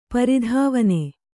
♪ pari dhāvane